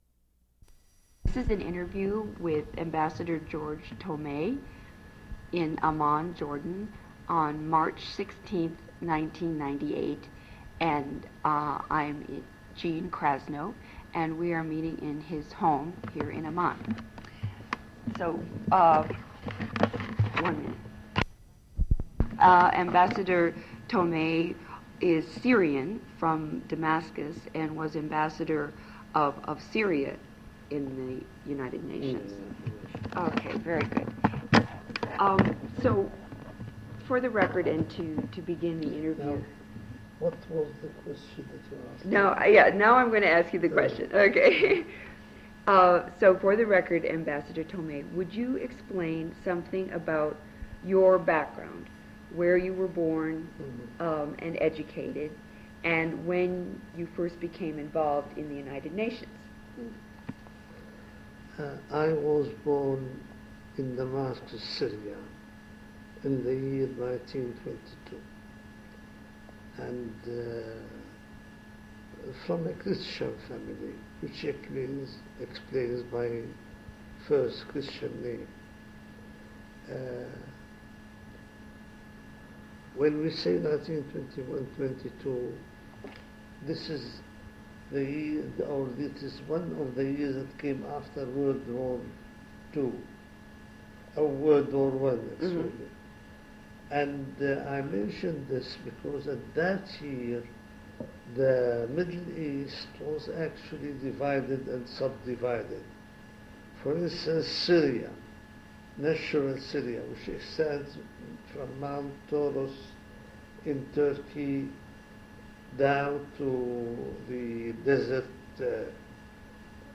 Interview with Ambassador George J. Tomeh /